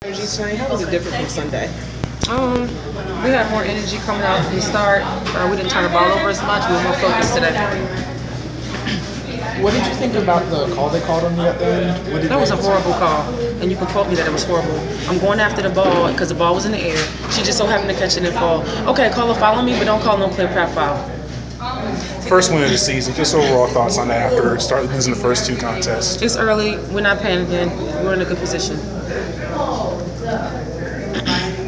inside the Inquirer: Postgame presser with Atlanta Dream’s Angel McCoughtry 6.11.15